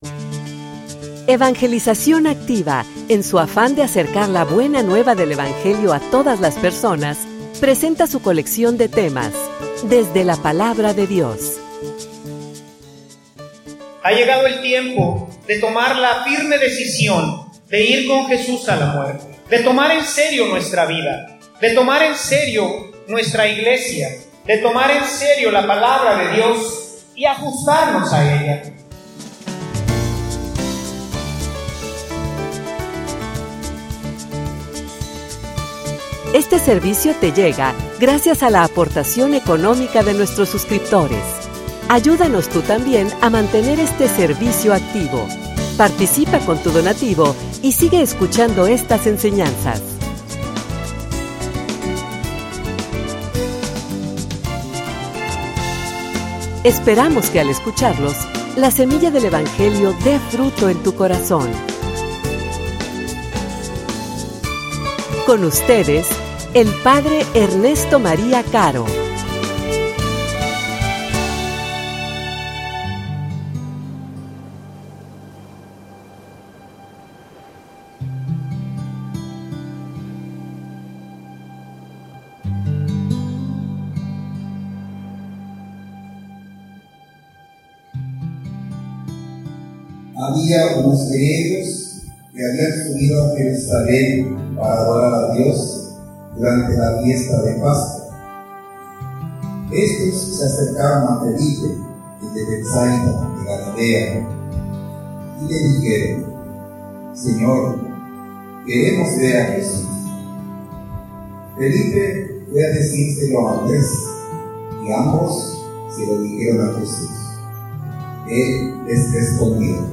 homilia_Ha_llegado_la_hora.mp3